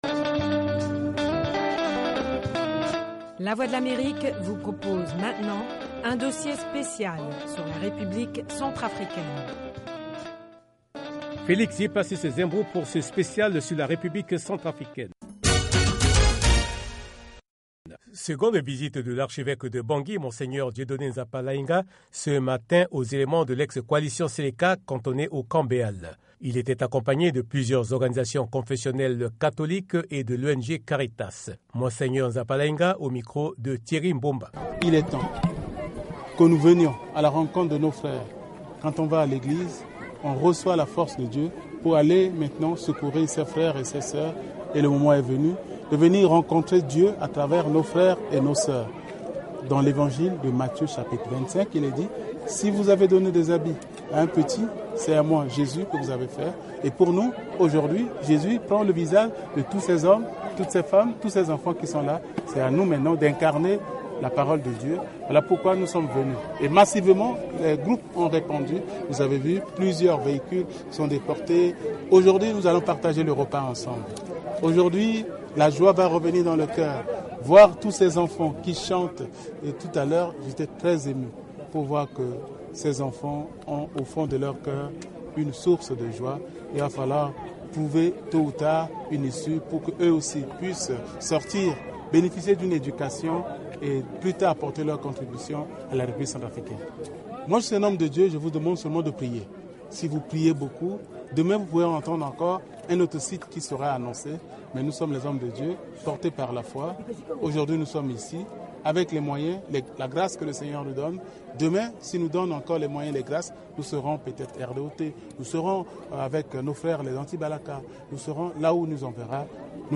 RCA: Reportage Special